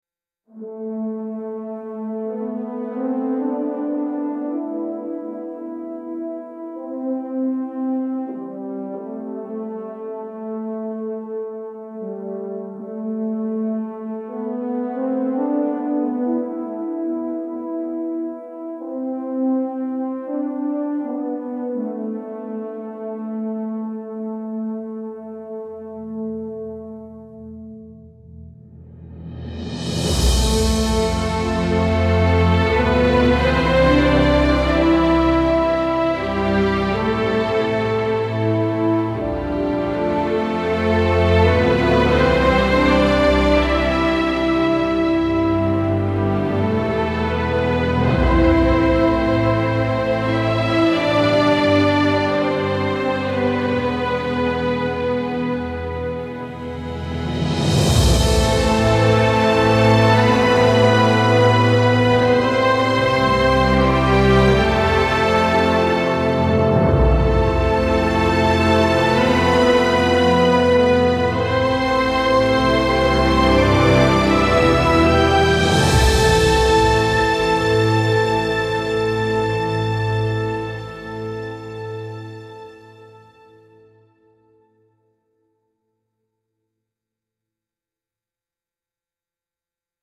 dignified memorial music with muted brass and reverent tone